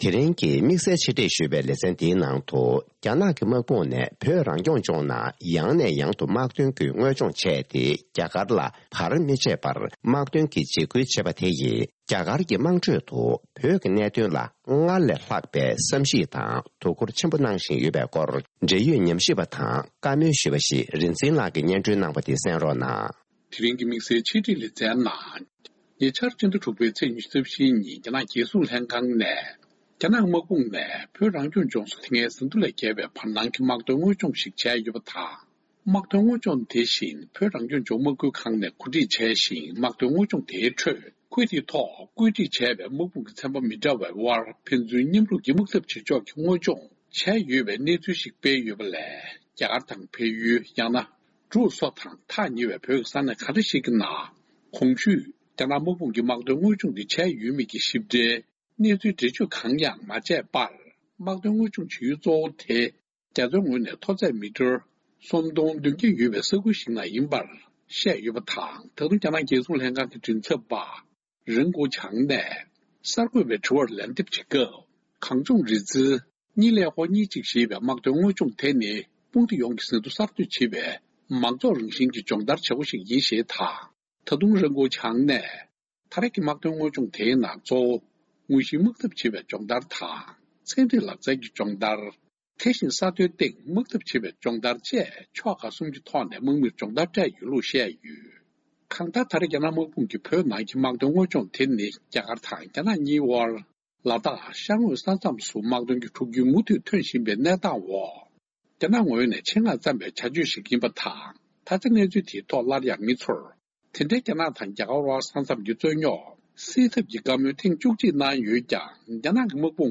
སྒྲ་ལྡན་གསར་འགྱུར།
དེ་རིང་གི་དམིགས་བསལ་ཆེད་སྒྲིག་ལེ་ཚན་ནང་།རྒྱ་ནག་གི་དམག་དཔུང་ནས་བོད་རང་སྐྱོང་ལྗོངས་ནང་ཡང་ཡང་དམག་དོན་གྱི་དངོས་སྦྱོང་བྱས་ཏེ་རྒྱ་གར་ལ་བར་མི་ཆད་པར་དམག་དོན་གྱི་འཇིགས་སྐུལ་བྱས་པ་དེས་།རྒྱ་གར་མི་དམངས་ཁྲོད་བོད་ཀྱི་གནད་དོན་ཐོག་སྔར་ལས་ལྷག་པའི་བསམ་གཞིགས་དང་དོ་འཁུར་ཆེན་པོ་གནང་བཞིན་ཡོད་པའི་སྐོར་འབྲེལ་ཡོད་ཉམས་ཞིབ་པ་དང་བཀའ་མོལ་ཞུས་པ་ཞིག་ལ་གསན་རོགས།